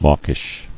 [mawk·ish]